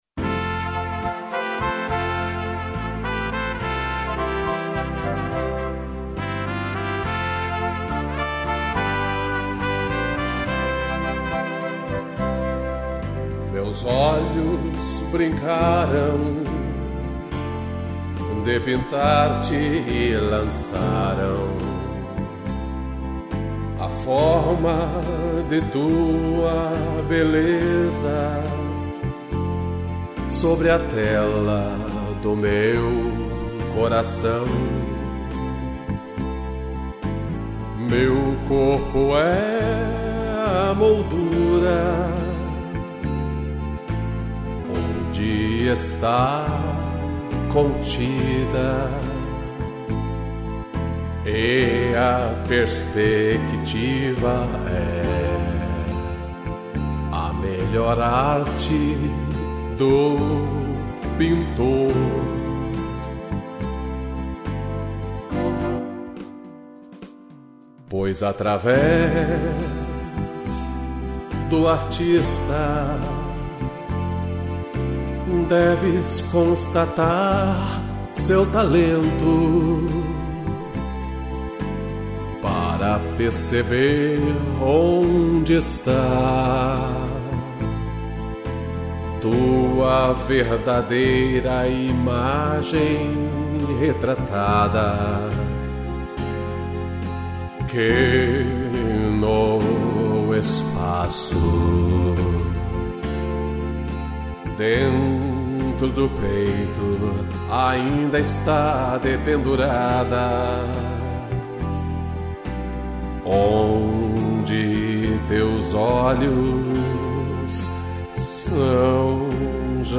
Voz